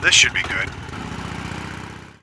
星际争霸音效-terran-raynorv-urvyes02.wav